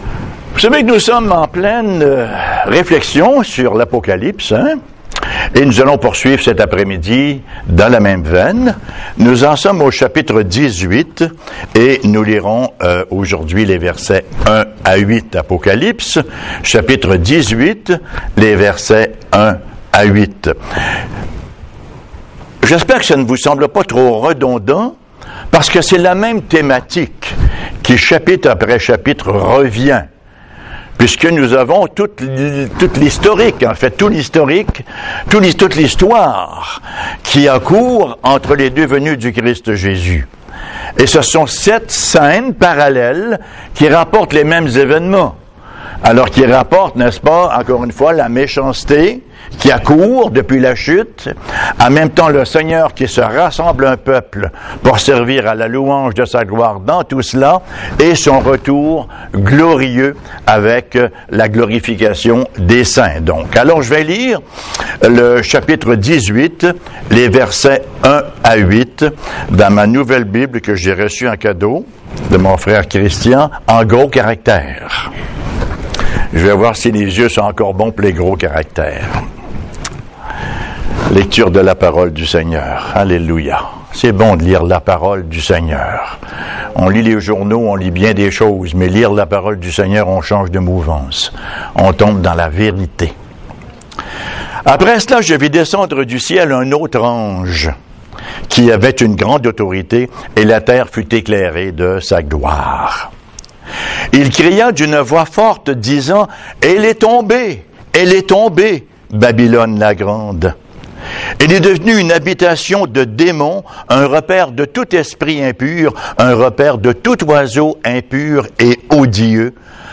Serie de sermons